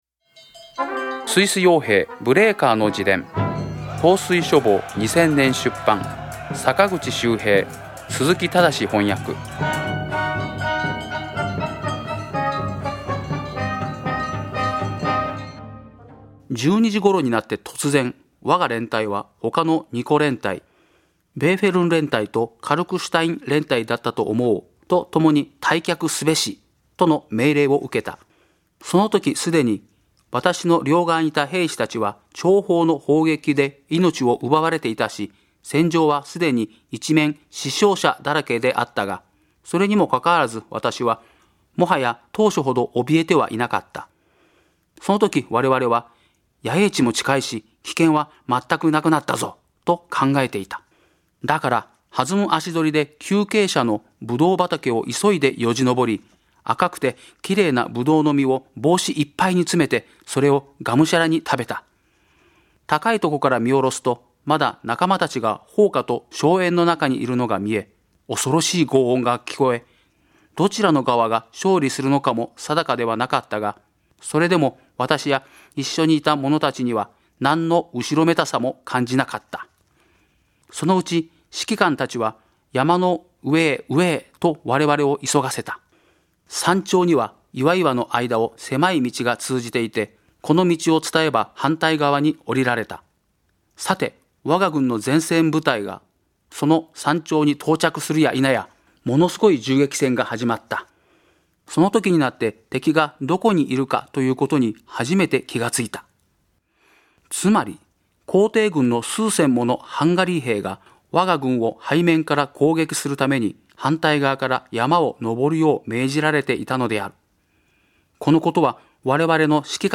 朗読『スイス傭兵ブレーカーの自伝』第60回